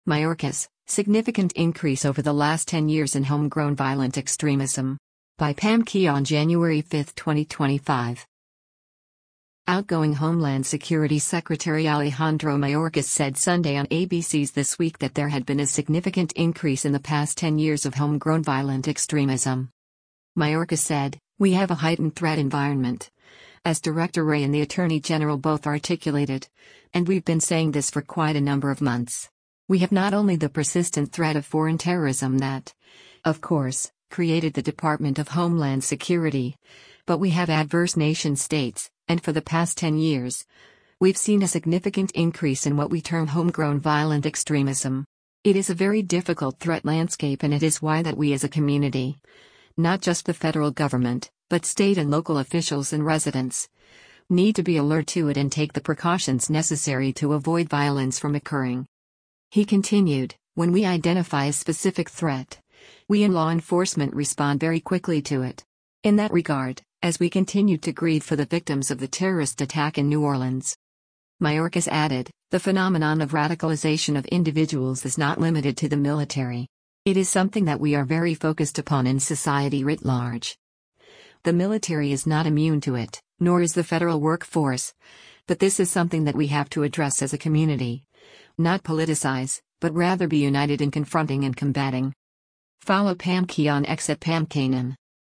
Outgoing Homeland Security Secretary Alejandro Mayorkas said Sunday on ABC’s “This Week” that there had been a “significant increase” in the past 10 years of homegrown violent extremism.